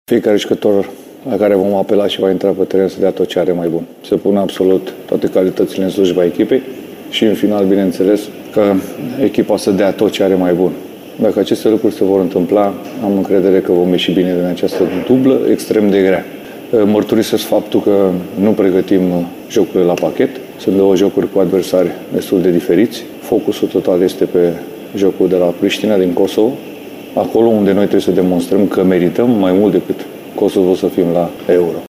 Cu ocazia acestei sesiuni de declarații, la FRFTV, tehnicianul a vorbit totuși despre ambele adversare: